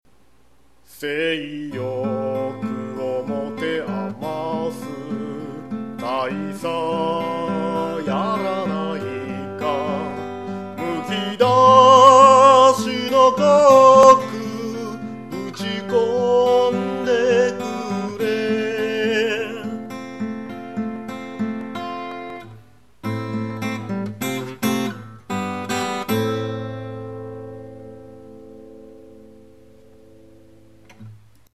録音環境が整ってないので音割れしまくるのは仕様！！